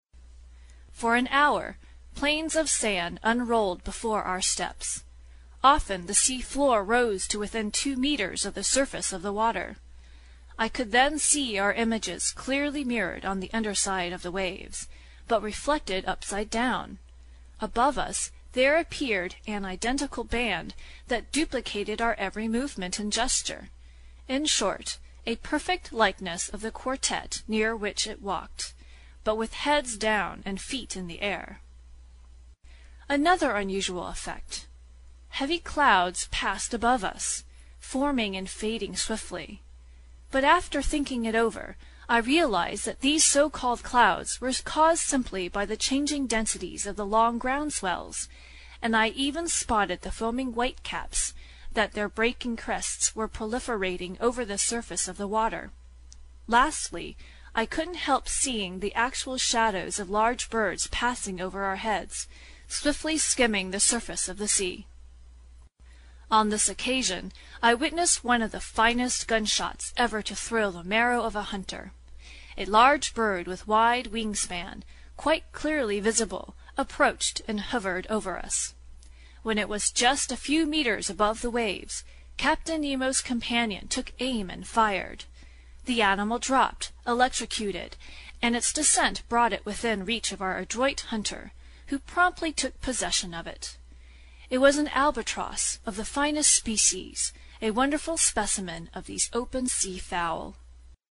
英语听书《海底两万里》第228期 第17章 海底森林(9) 听力文件下载—在线英语听力室
在线英语听力室英语听书《海底两万里》第228期 第17章 海底森林(9)的听力文件下载,《海底两万里》中英双语有声读物附MP3下载